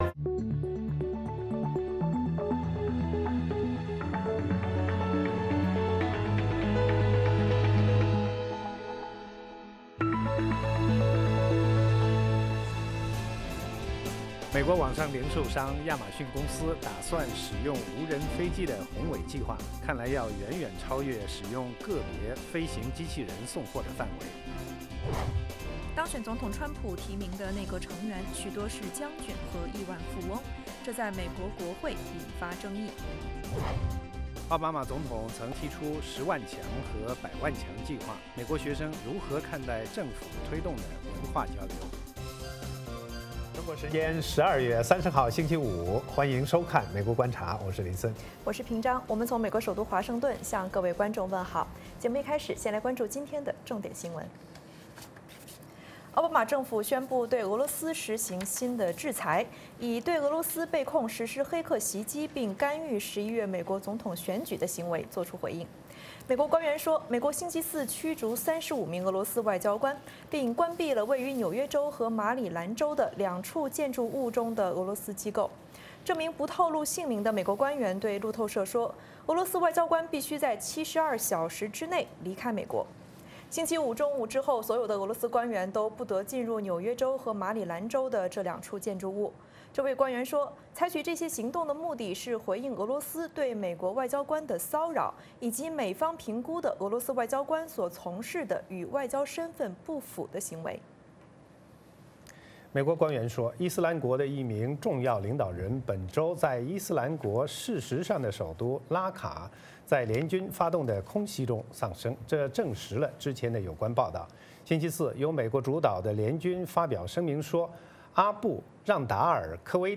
“VOA卫视 美国观察”掌握美国最重要的消息，深入解读美国选举，政治，经济，外交，人文，美中关系等全方位话题。节目邀请重量级嘉宾参与讨论。